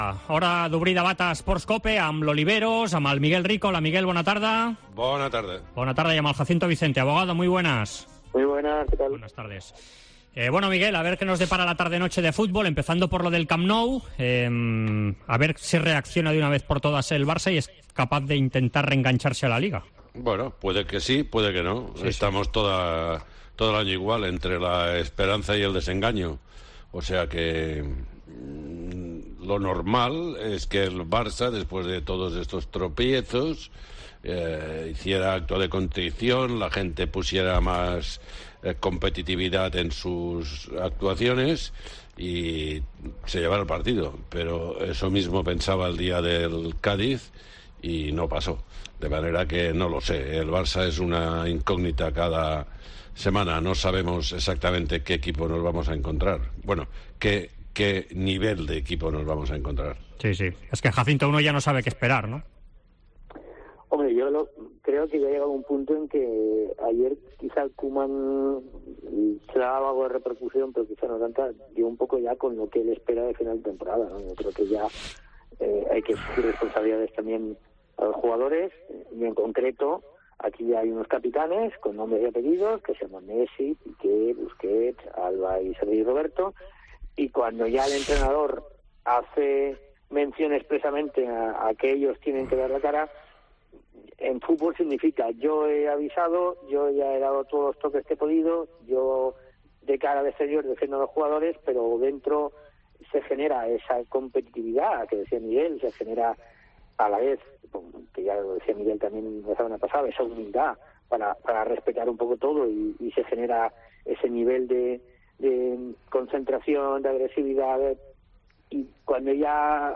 Debat Esports COPE